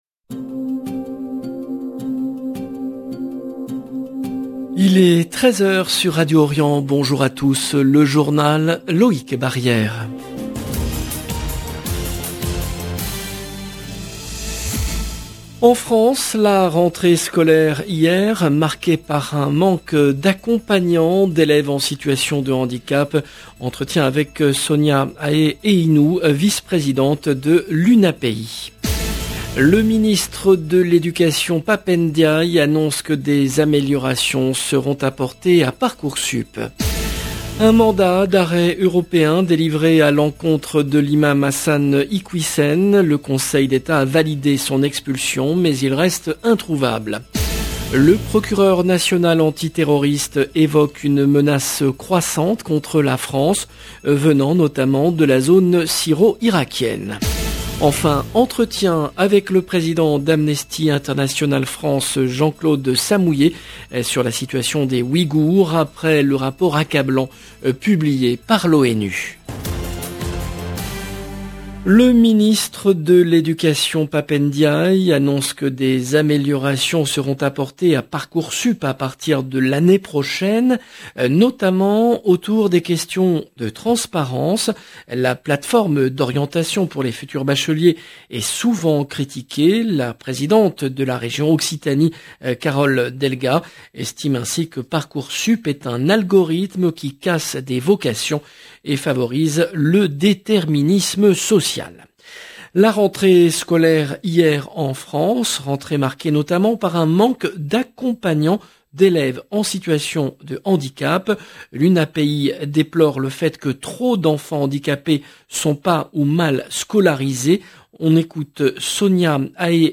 LE JOURNAL DE 13 H EN LANGUE FRANCAISE DU 2/09/22